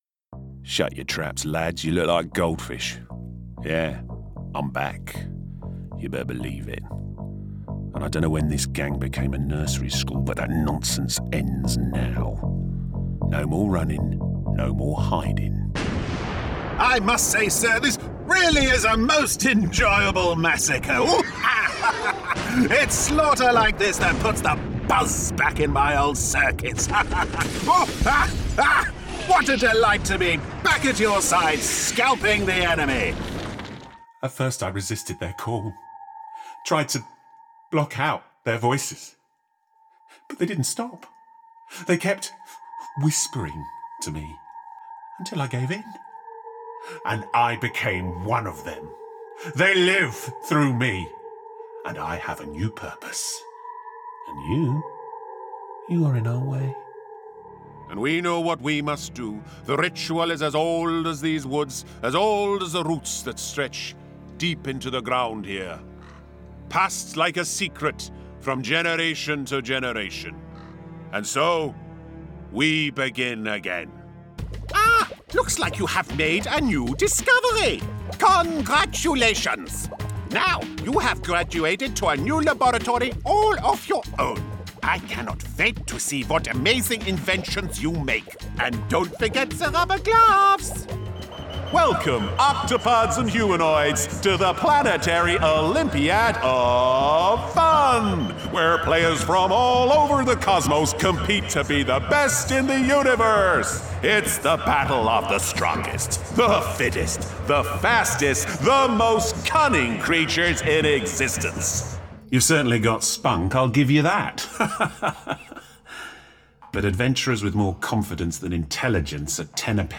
Video Game Showreel
Male
Neutral British
Playful
Quirky
Upbeat